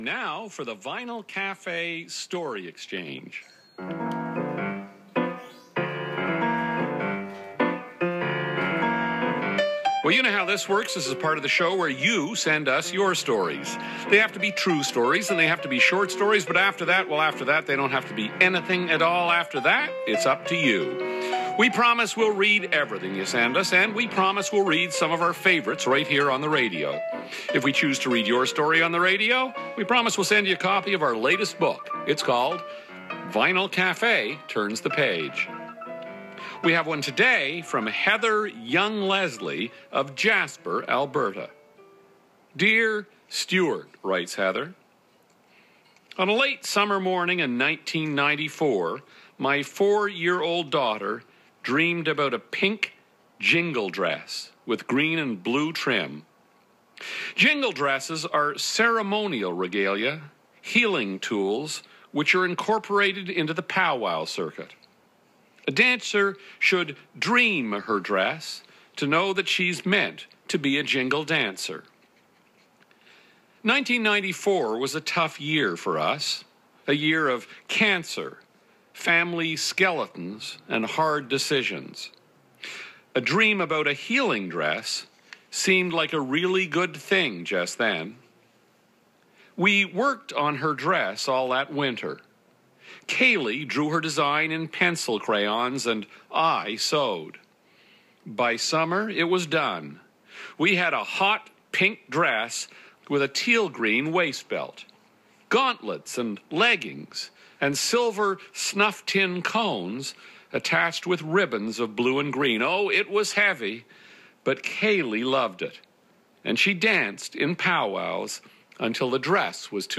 The story lauds the small community of Jasper, Alberta. It was broadcast as part of the "Indigenous Music" episode, aired on June 3 & 4, 2016.